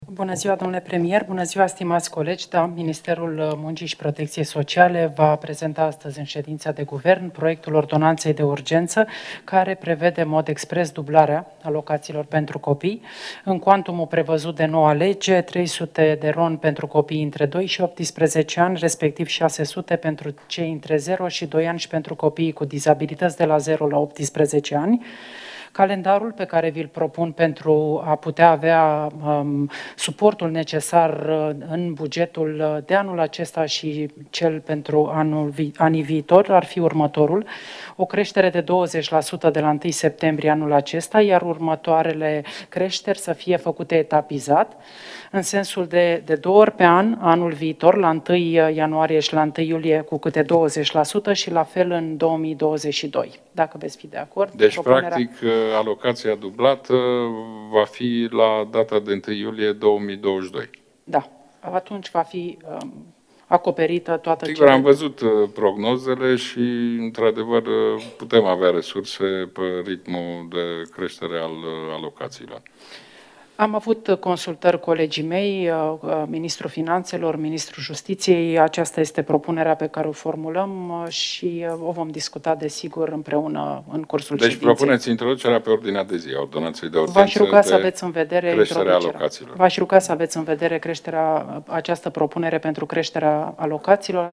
Ministrul Muncii, Violeta Alexandru, în dialog cu premierul Ludovic Orban, la începutul ședinței guvernului de miercuri seară:
Violeta-Alexandru-si-Ludovic-Orban.mp3